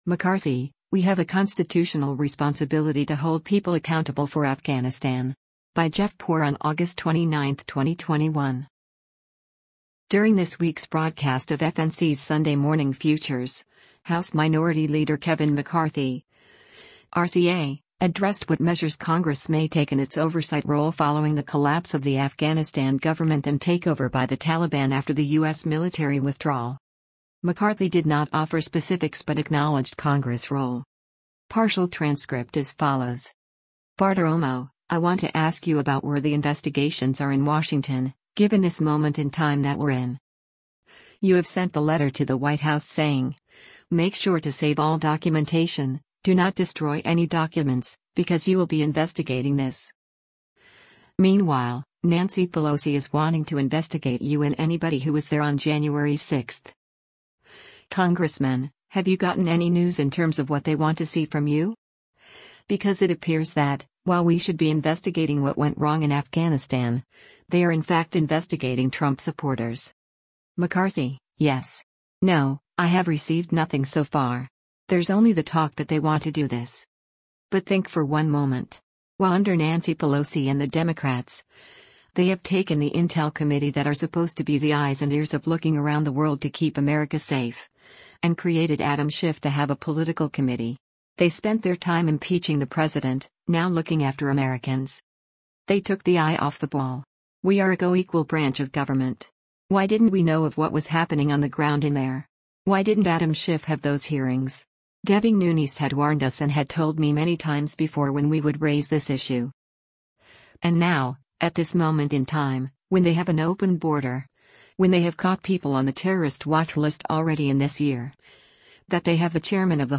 During this week’s broadcast of FNC’s “Sunday Morning Futures,” House Minority Leader Kevin McCarthy (R-CA) addressed what measures Congress may take in its oversight role following the collapse of the Afghanistan government and takeover by the Taliban after the U.S. military withdrawal.